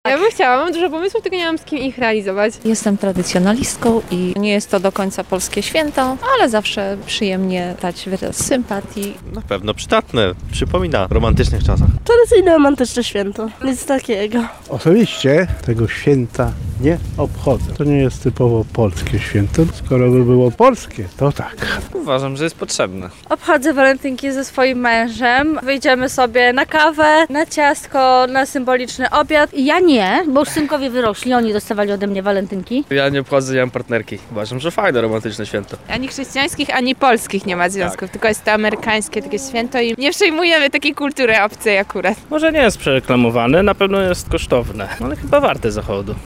[SONDA] Czy lublinianie świętują imieniny Walentego?
Zapytaliśmy mieszkańców Lublina, czy obchodzą Walentynki: